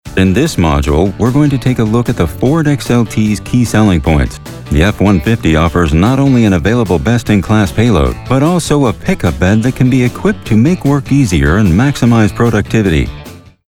Mature Adult, Adult
Has Own Studio
standard us
07_Ford_150_spot.mp3